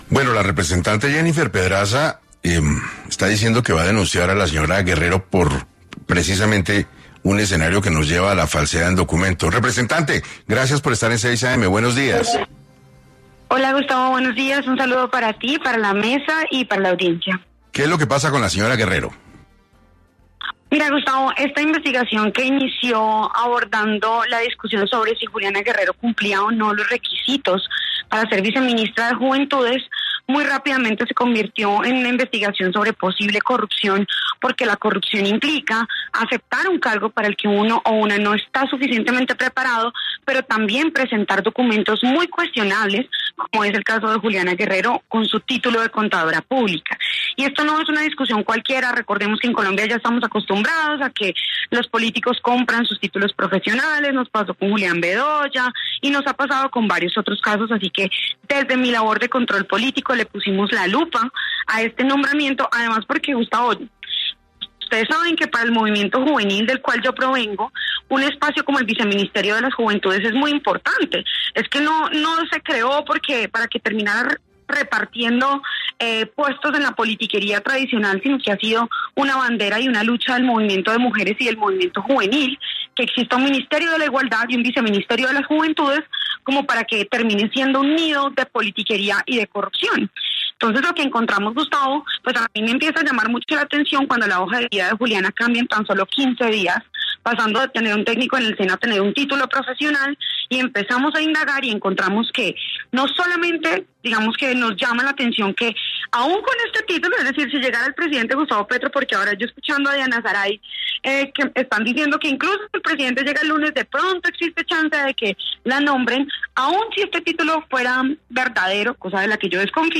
La representante a la Cámara, Jennifer Pedraza, pasó por 6AM para hablar del caso de esta mujer, que hoy es considerado como la ‘papa caliente’ del Gobierno.